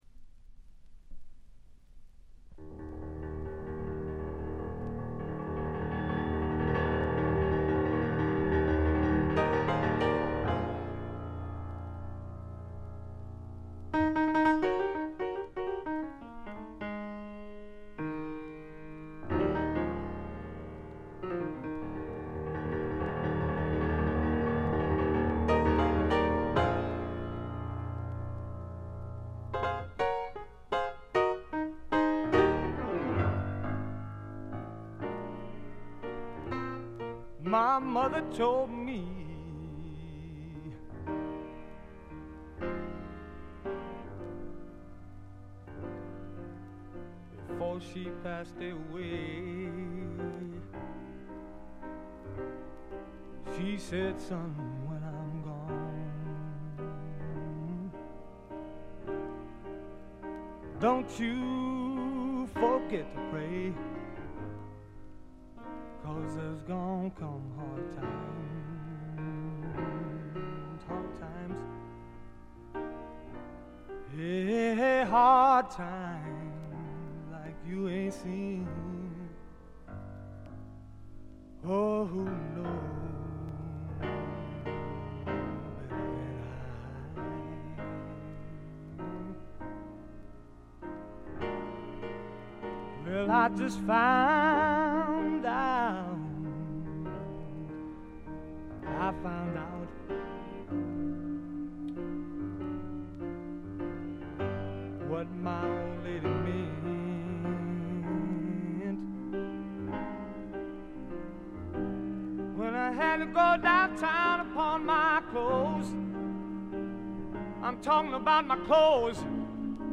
ほとんどノイズ感無し。
ブルース･ロックとスワンプ・ロックを混ぜ合わせて固く絞ったような最高にグルーヴィでヒップなアルバムです。
試聴曲は現品からの取り込み音源です。